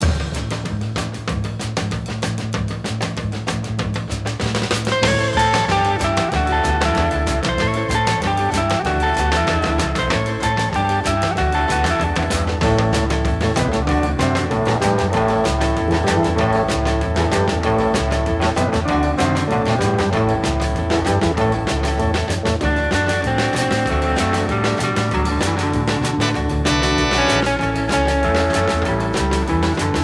Twist